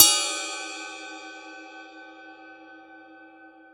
• Ambient Ride Sound Sample F Key 06.wav
Royality free ride cymbal drum sample tuned to the F note.
ambient-ride-sound-sample-f-key-06-6M3.wav